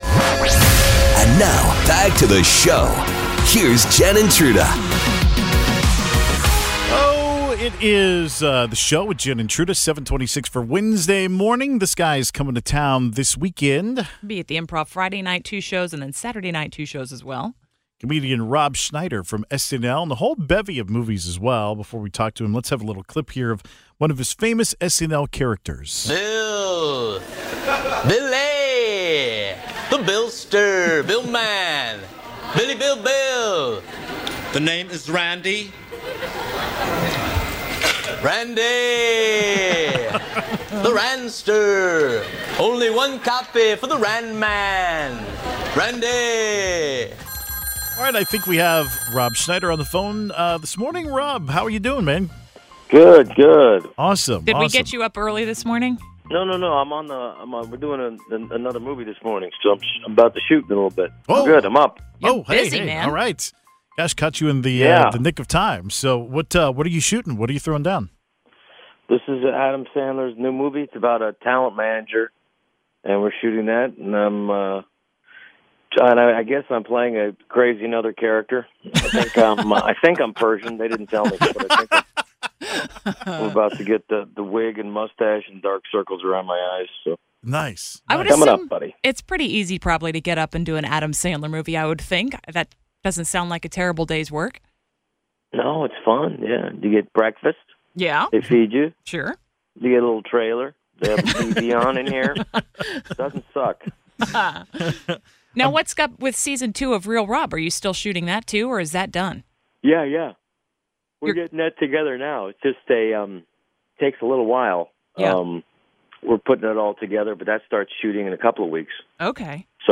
Rob Schneider (SNL, Happy Gilmore, Grown Ups, etc) called in to chat with The Show this morning, live from the set of the brand new Adam Sandler film. During the course of conversation Rob discovered that The Show was about to experience "cupping" and revealed that he's done it for years.